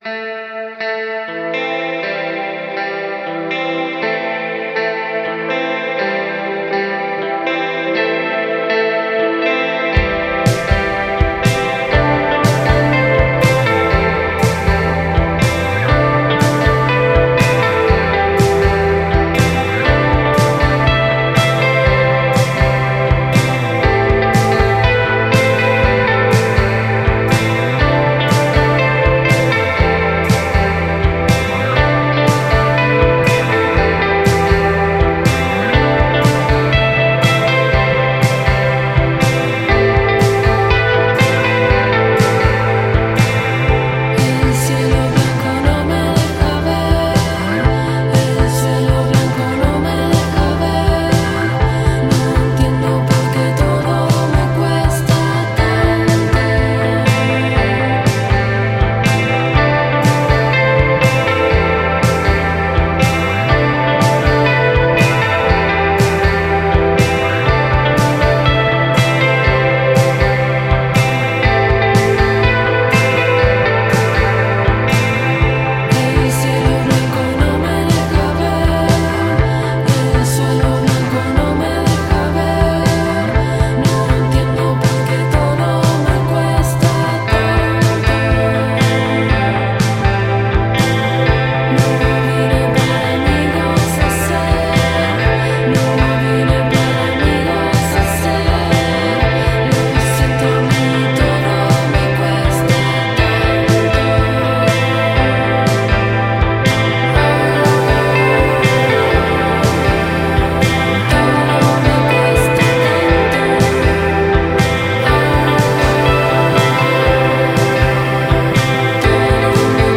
Argentina is the creative force behind indie rock project
This third track on the release is a beautiful daydream […]